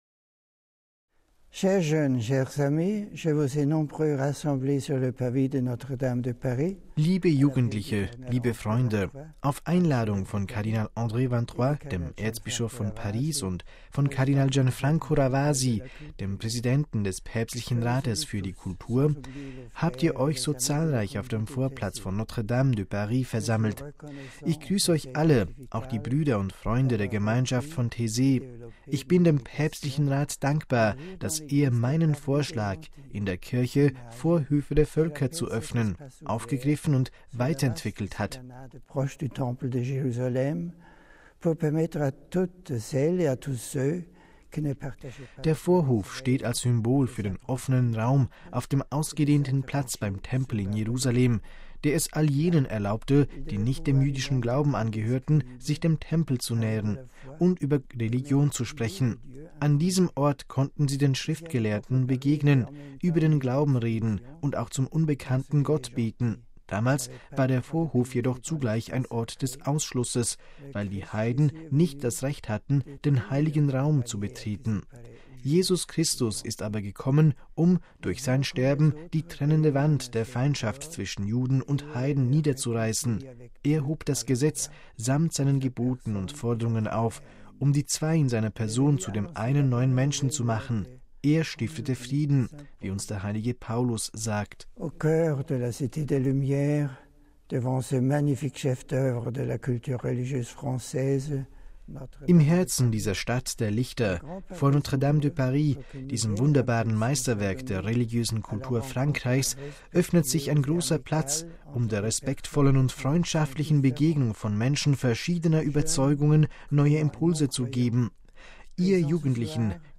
Die Ansprache wurde am Freitagabend bei einem Jugendtreffen in Paris veröffentlicht.